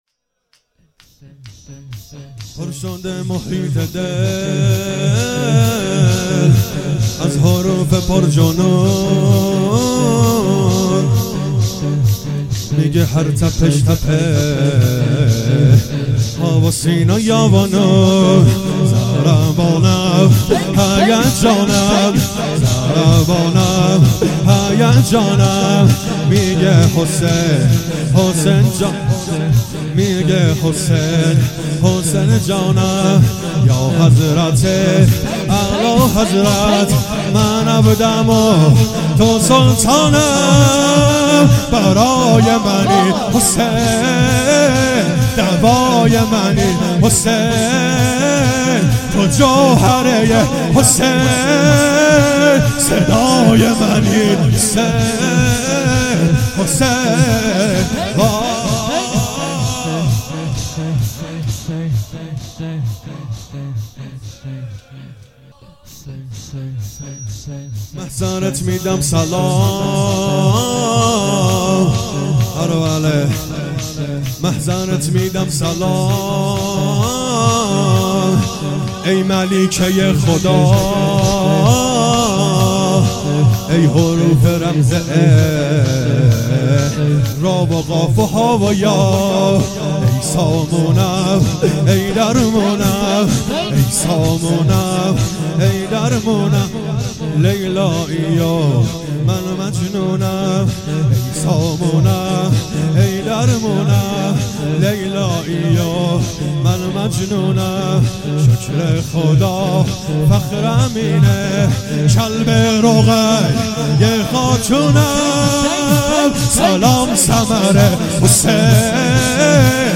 شور بسیار زیبا (برای منی حسین)